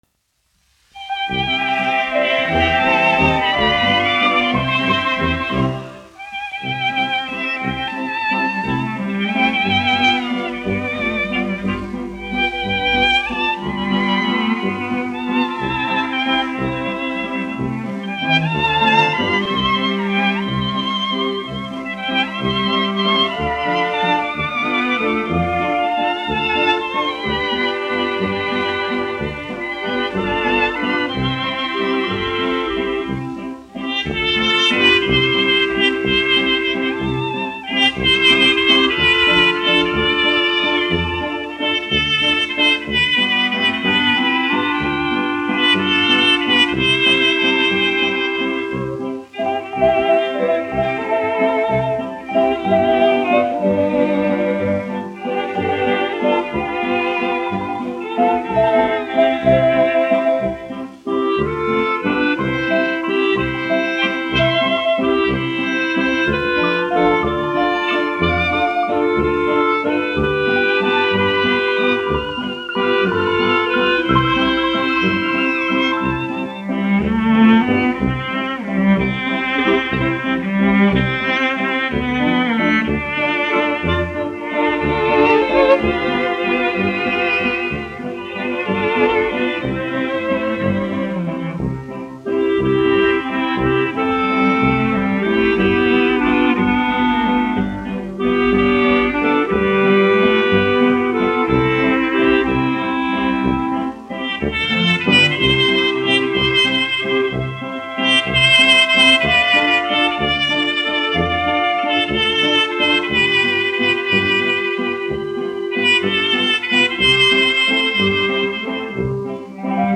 1 skpl. : analogs, 78 apgr/min, mono ; 25 cm
Valši
Latvijas vēsturiskie šellaka skaņuplašu ieraksti (Kolekcija)